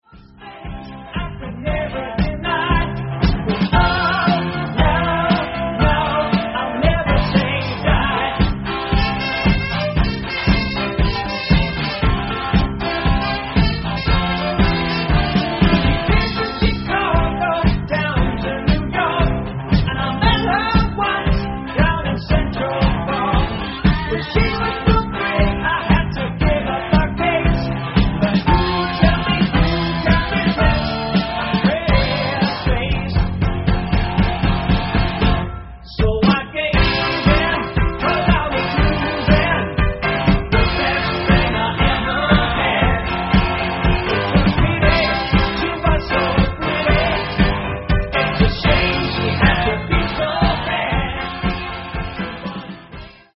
Recorded at The Old Smithy and Highland Studios